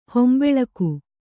♪ hombeḷaku